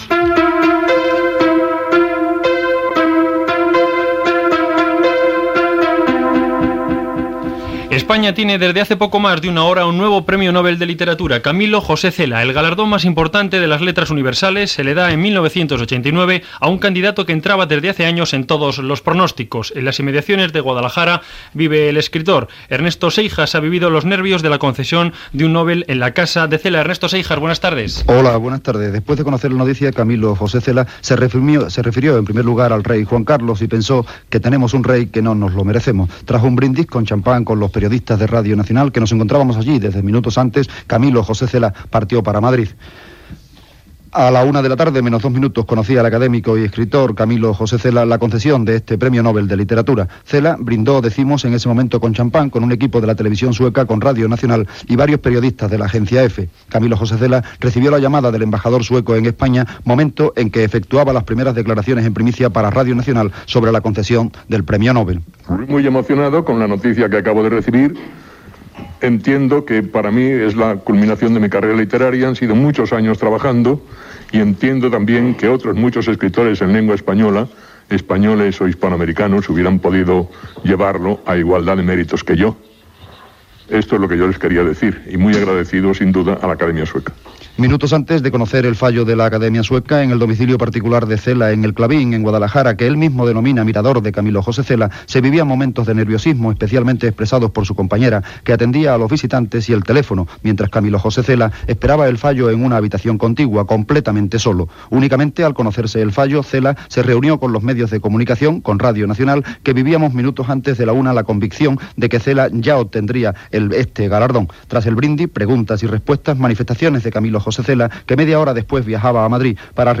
Connexió amb la seva casa a Guadaljara i entrevista informativa a l'escriptor.
Gènere radiofònic Informatiu